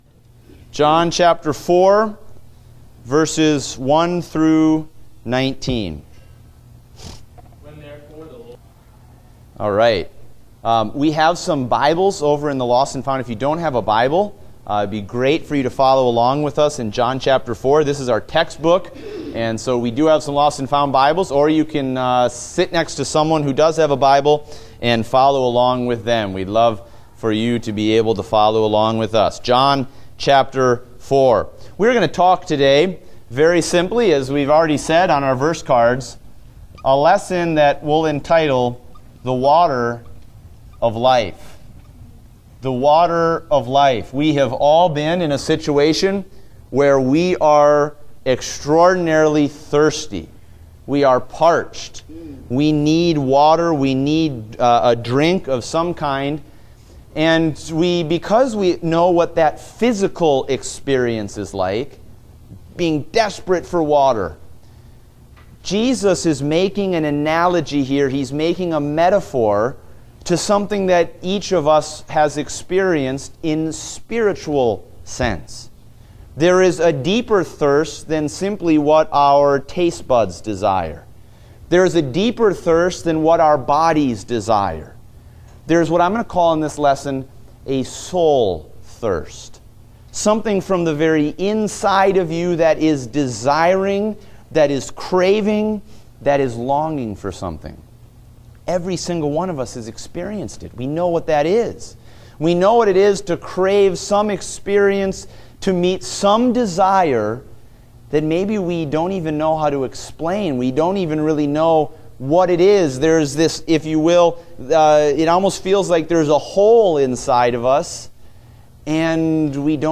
Date: June 12, 2016 (Adult Sunday School)